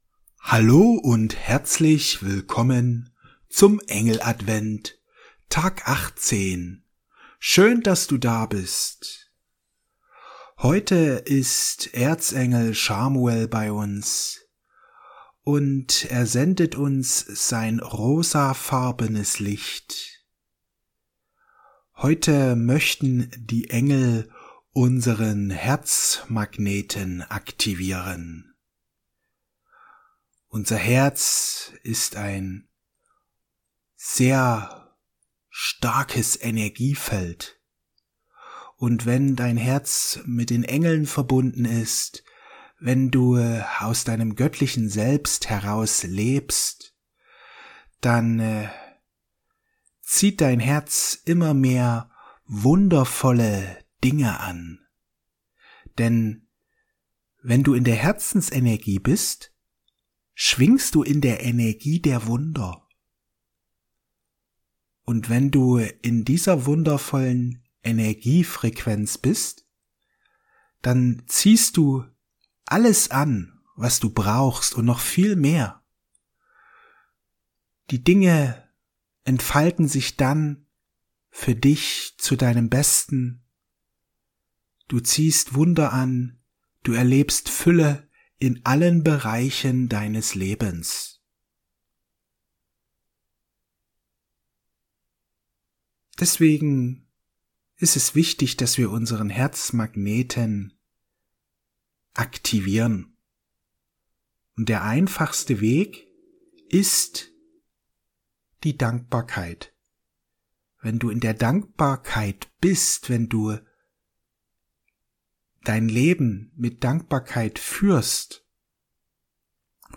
Die Aktivierung deines Herzmagneten Meditation mit Erzengel Chamuel